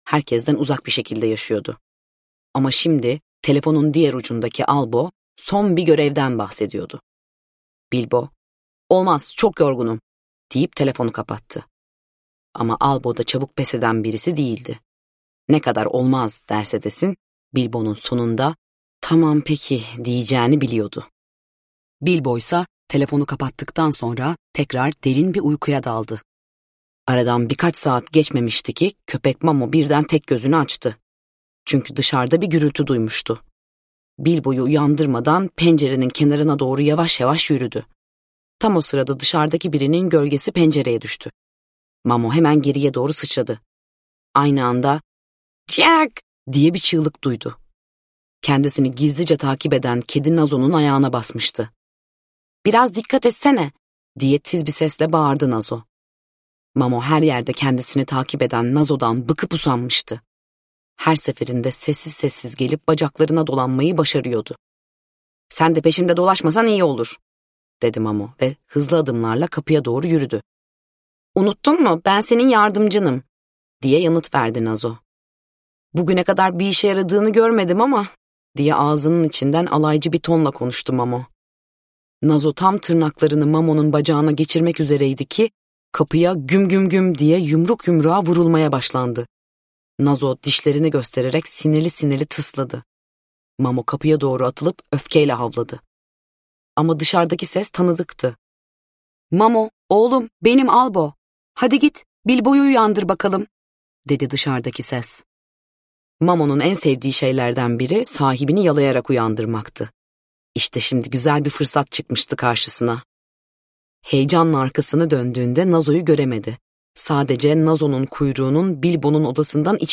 Ajan Bilbo - Seslenen Kitap
Seslendiren